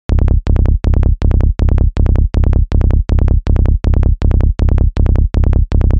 Psy Bass
标签： 160 bpm Psychedelic Loops Bass Synth Loops 1.01 MB wav Key : D
声道立体声